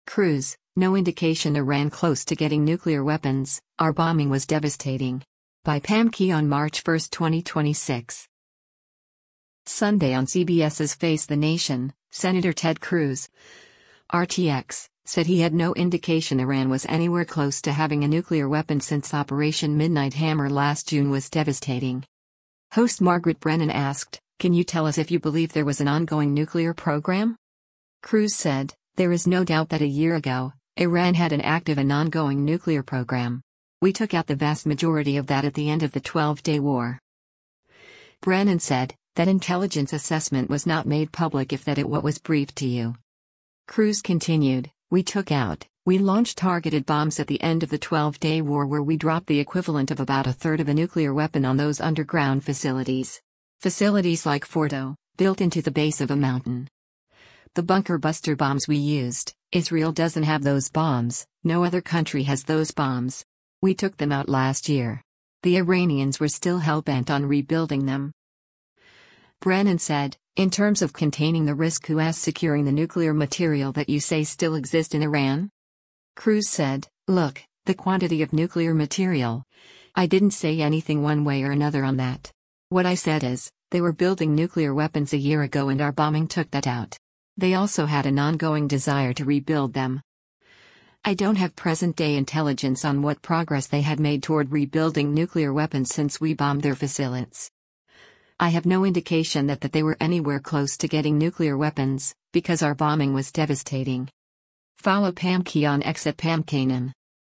Sunday on CBS’s “Face the Nation,’ Sen. Ted Cruz (R-TX) said he had “no indication” Iran was “anywhere close” to having a nuclear weapon since Operation Midnight Hammer last June “was devastating.”